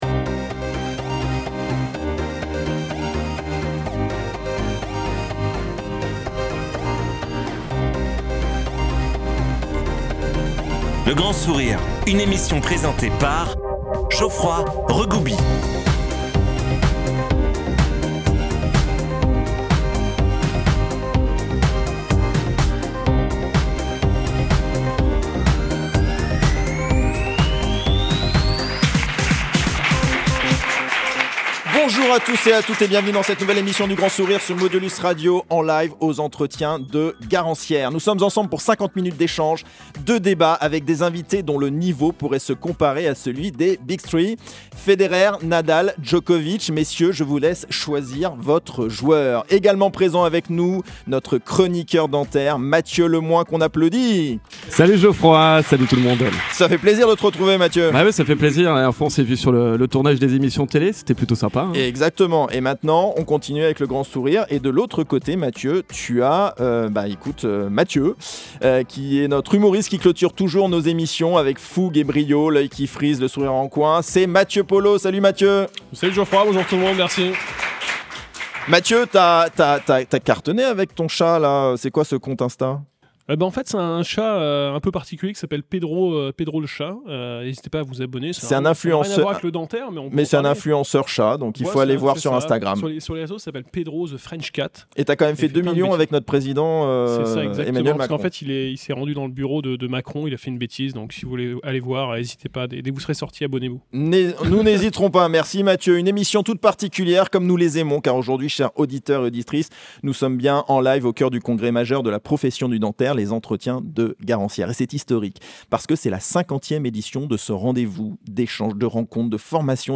Excellence médicale vs. rentabilité : le grand débat du cabinet dentaire
Un échange sans concession entre vision humaniste et réalité entrepreneuriale, pour penser le cabinet dentaire de demain.